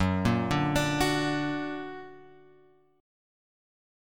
F#+ chord {2 5 4 3 3 x} chord